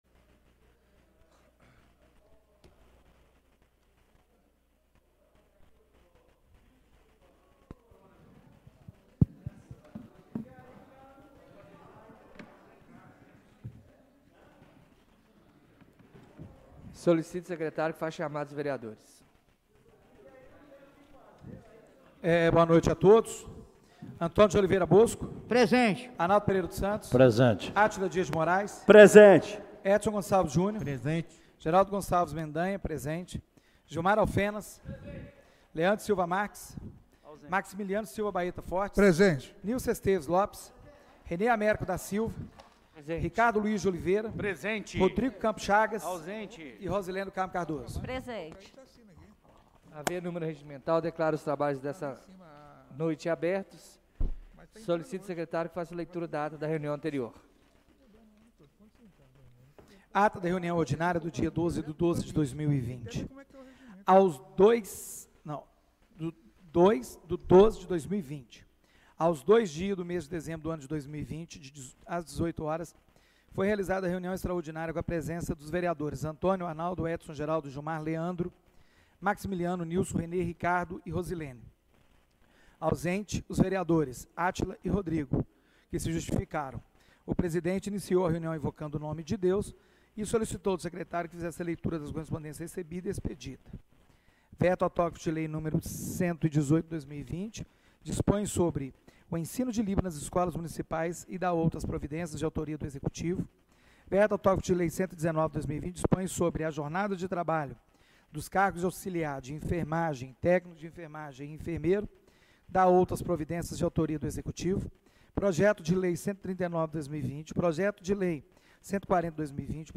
Reunião Extraordinária do dia 03/12/2020